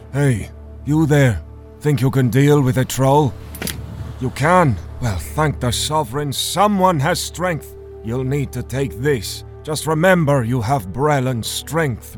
Norse Adult